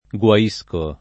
vai all'elenco alfabetico delle voci ingrandisci il carattere 100% rimpicciolisci il carattere stampa invia tramite posta elettronica codividi su Facebook guaire v.; guaisco [ gU a &S ko ], ‑sci — ger. guaendo [ gU a $ ndo ]